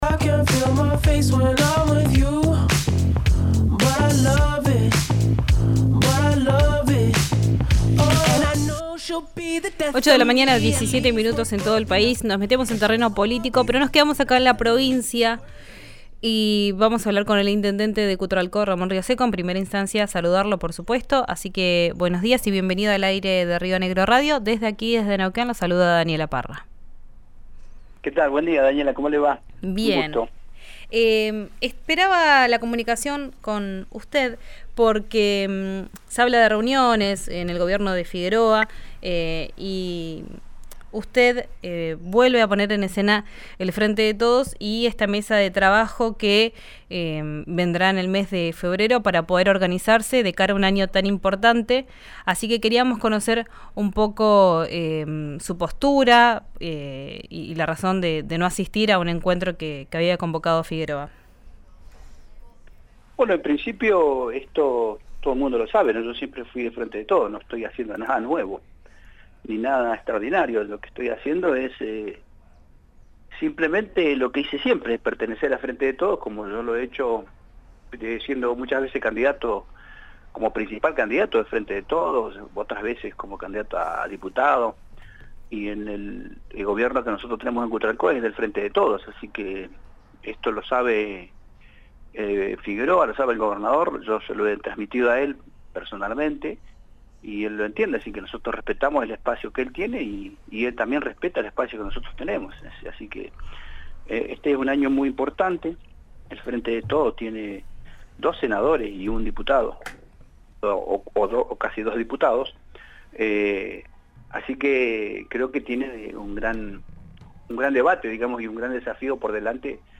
Escuchá a Ramón Rioseco en el aire de RÍO NEGRO RADIO: